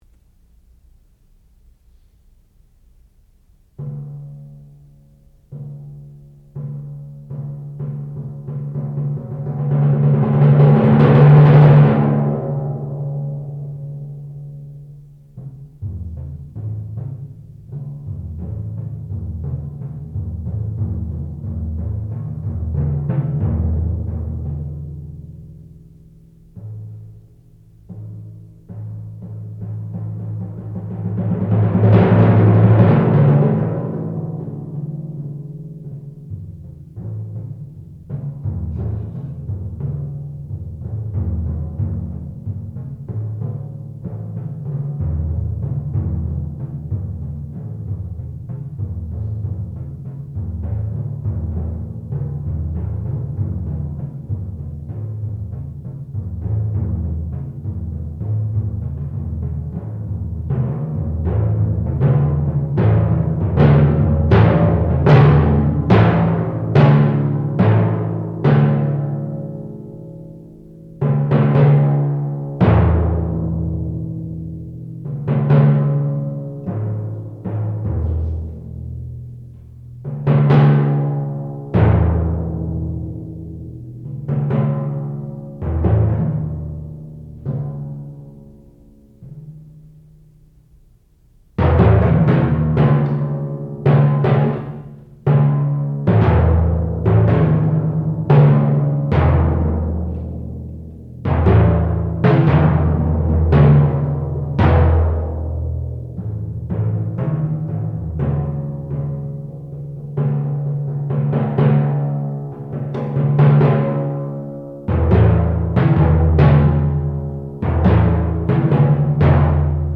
sound recording-musical
classical music
Master Recital